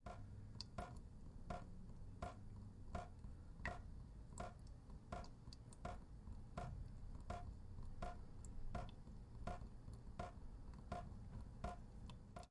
水槽厨房
描述：在水滴的时候记录在厨房里。
Tag: 厨房 水槽 家庭 房子